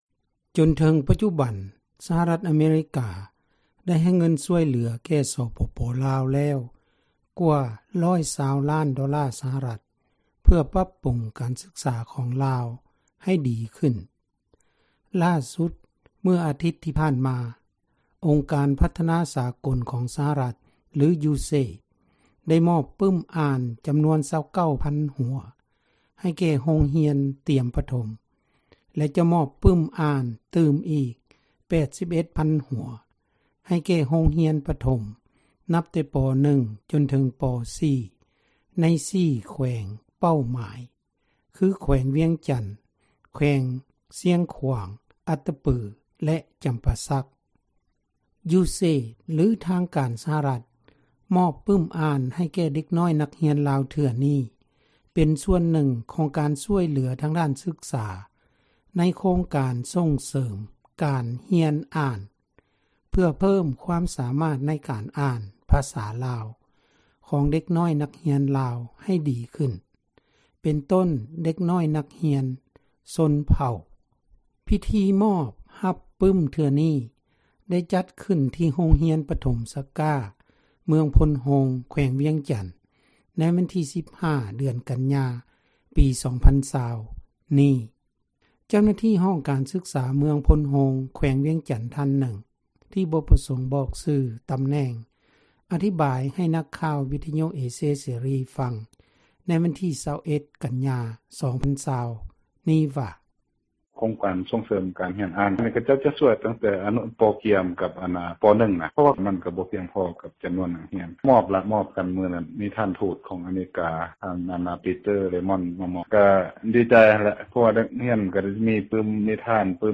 ເຈົ້າໜ້າທີ່ ຫ້ອງການສຶກສາ ເມືອງໂພນໂຮງ ແຂວງວຽງຈັນ ທ່ານນຶ່ງ ອະທິບາຍ ໃຫ້ນັກຂ່າວ ວິທຍຸເອເຊັຽເສຣີ ຟັງໃນວັນທີ່ 21 ກັນຍາ 2020 ນີ້ວ່າ: